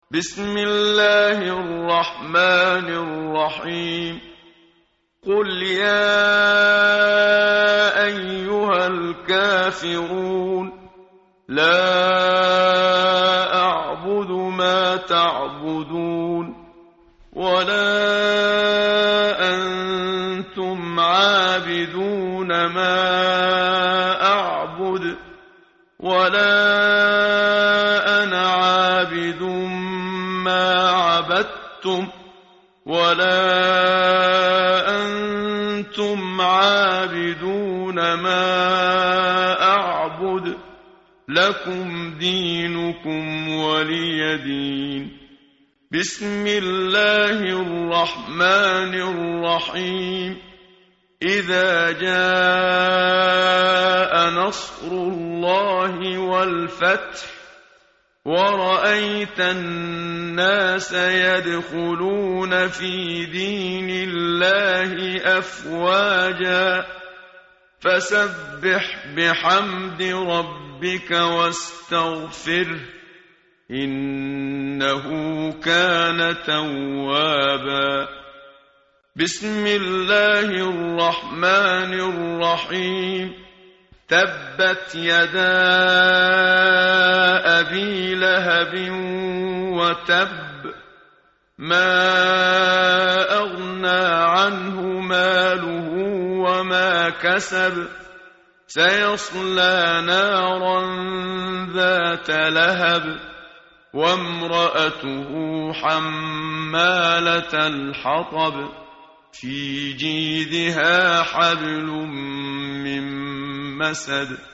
tartil_menshavi_page_603.mp3